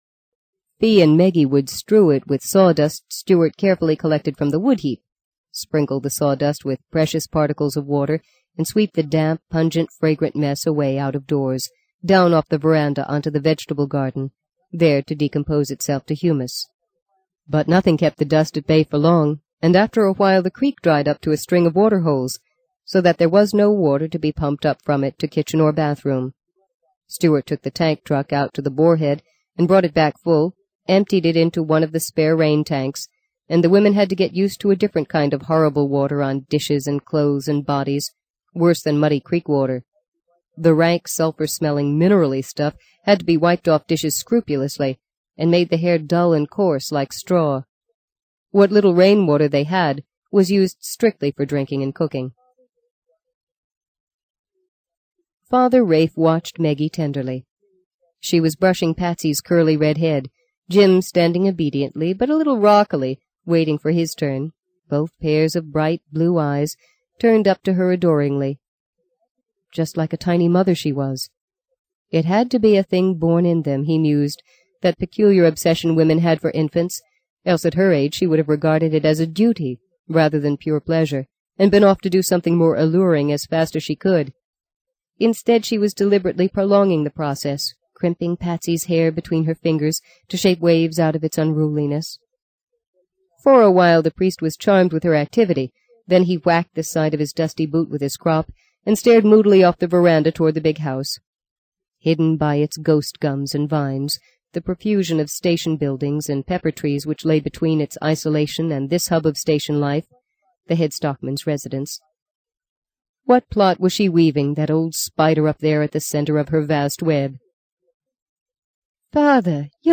在线英语听力室【荆棘鸟】第六章 06的听力文件下载,荆棘鸟—双语有声读物—听力教程—英语听力—在线英语听力室